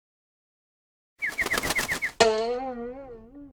SA_pecking_order.ogg